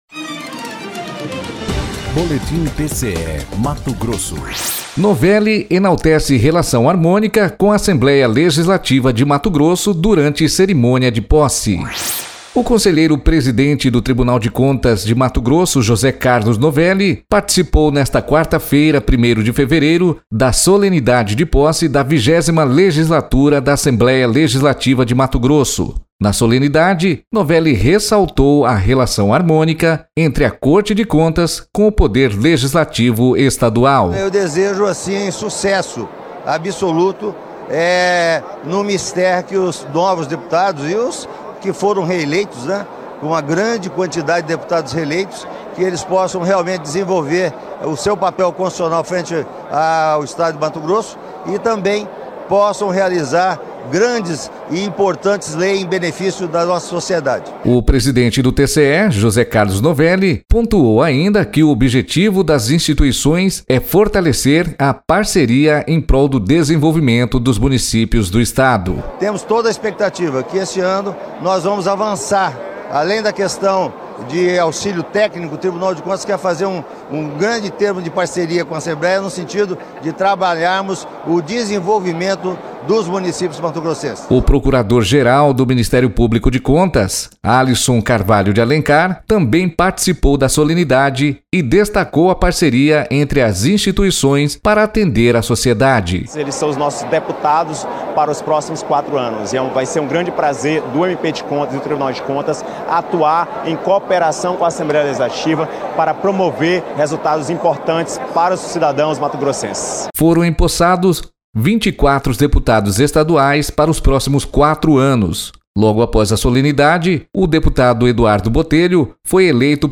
Sonora: José Carlos Novelli – conselheiro presidente do TCE-MT